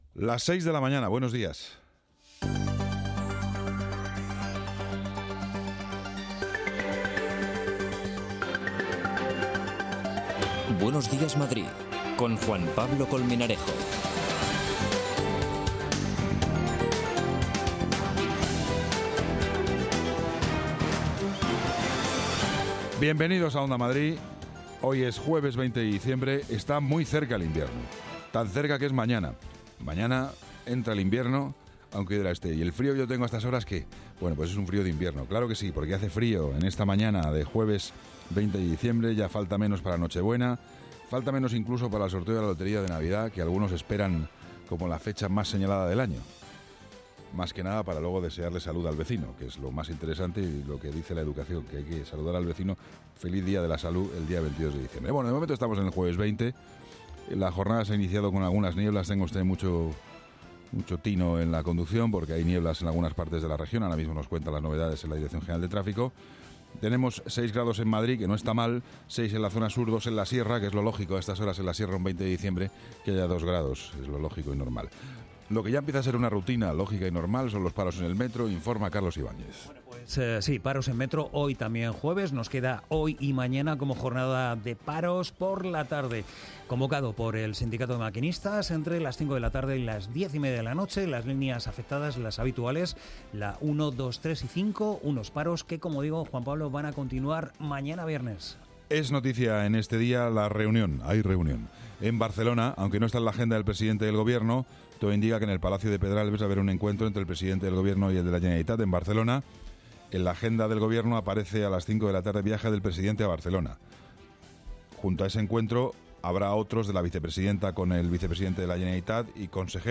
Actualidad, opinión, análisis, información de servicio público, conexiones en directo, entrevistas. Todo lo que necesitas para comenzar el día, desde el rigor y la pluralidad informativa.